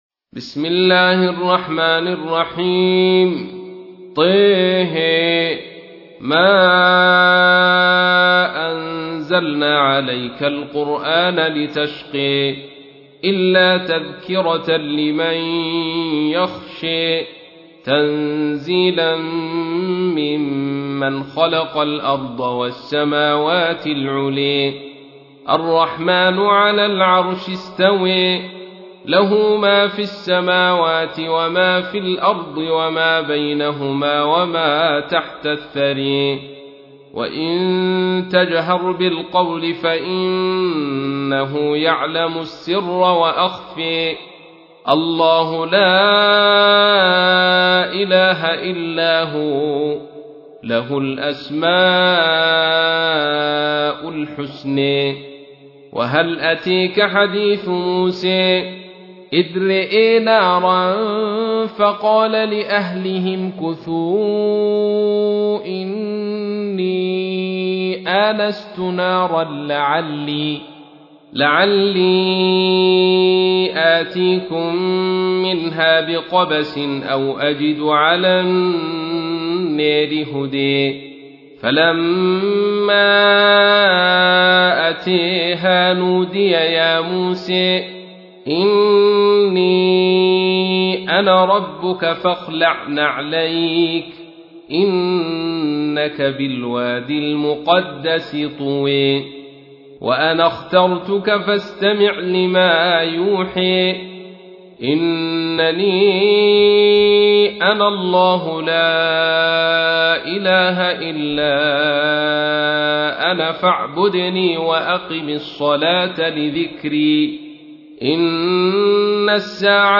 تحميل : 20. سورة طه / القارئ عبد الرشيد صوفي / القرآن الكريم / موقع يا حسين